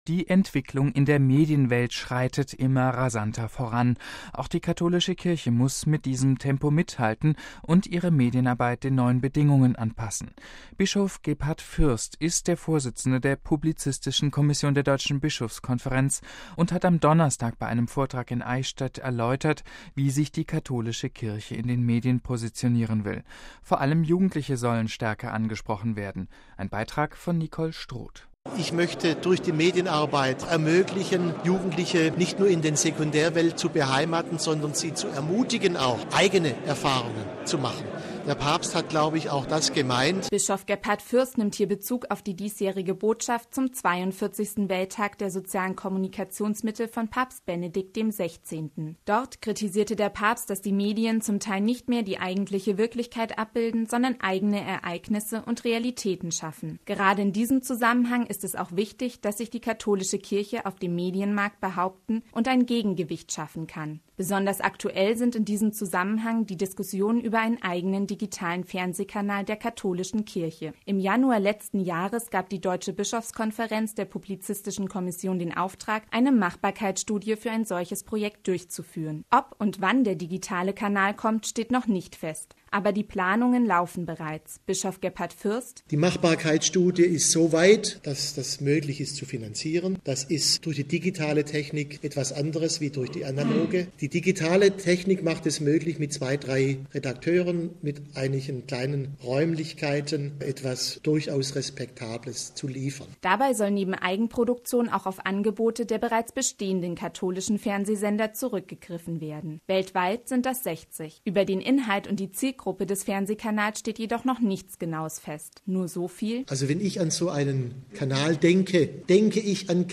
Bischof Gebhard Fürst ist der Vorsitzende der Publizistischen Kommission der Deutschen Bischofskonferenz und hat am Donnerstag bei einem Vortrag in Eichstätt erläutert, wie sich die Katholische Kirche in den Medien positionieren will.